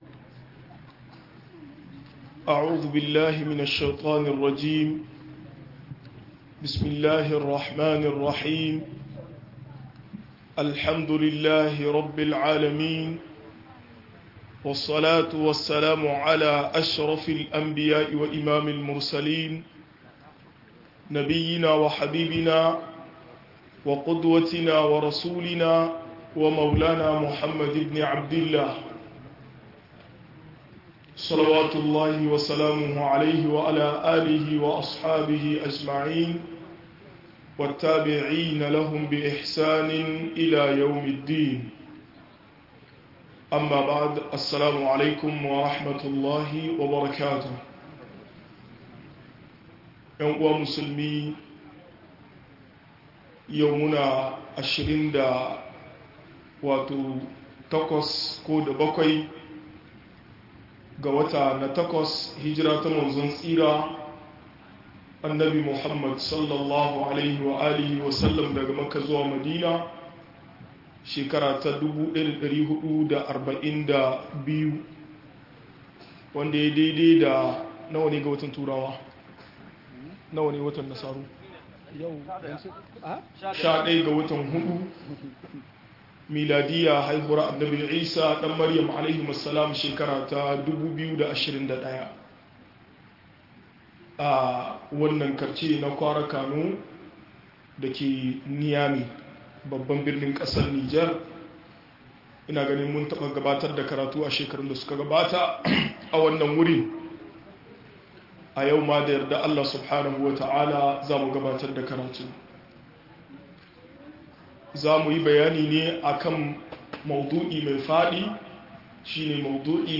Yadda ake neman ilimi - MUHADARA